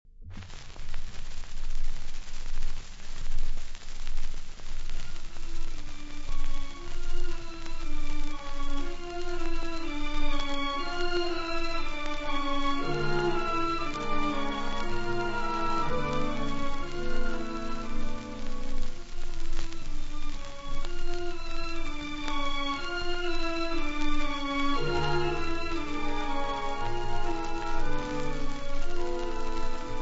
• orchestre
• Rhapsody
• registrazione sonora di musica